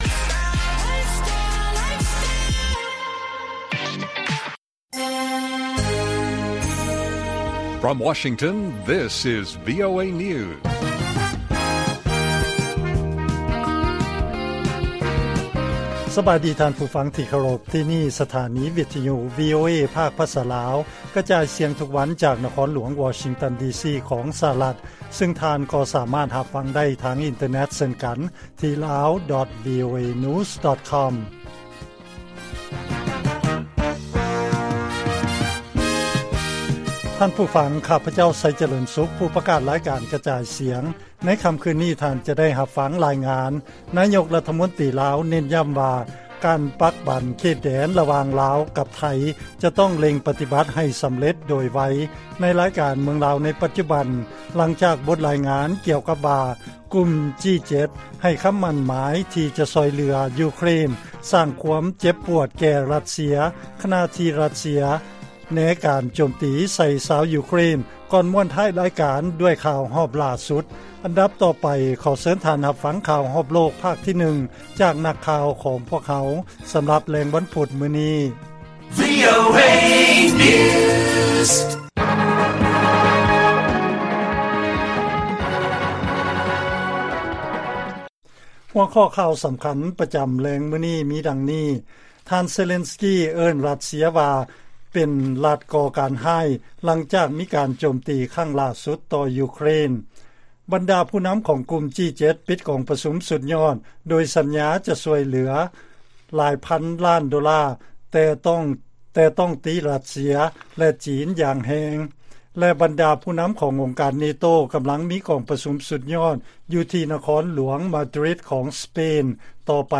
ລາຍການກະຈາຍສຽງຂອງວີໂອເອ ລາວ: ທ່ານເຊເລັນສ໌ກີ ເອີ້ນຣັດເຊຍວ່າເປັນ “ລັດກໍ່ການຮ້າຍ” ຫລັງຈາກມີການໂຈມຕີ ຄັ້ງຫລ້າສຸດ ຕໍ່ຢູເຄຣນ